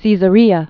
(sēzə-rēə, sĕsə-, sĕzə-)